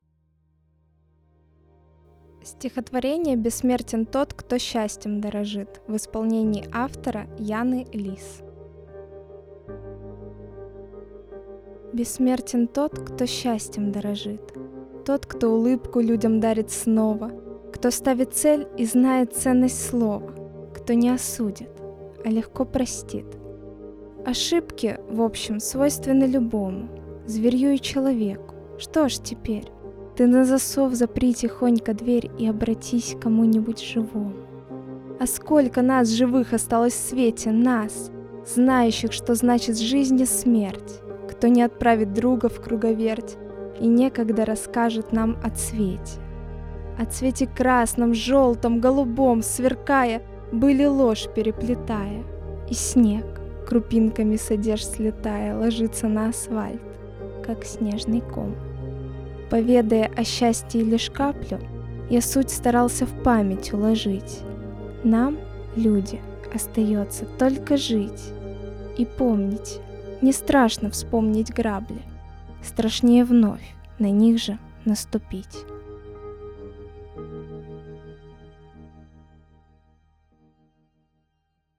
Каждый читатель сможет услышать в нём что-то родное и близкое, проникнуться теплотой слов и приятными голосами исполнителей.
Для вас читают: